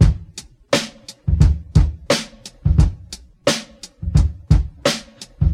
87 Bpm Breakbeat C# Key.wav
Free breakbeat sample - kick tuned to the C# note. Loudest frequency: 943Hz
87-bpm-breakbeat-c-sharp-key-G1n.ogg